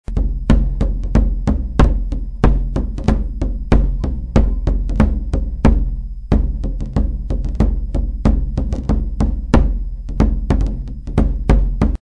URM Sonos de Sardigna : strumenti musicali antichi - Tumbarinu de Gavoi
Tamburogavoi.mp3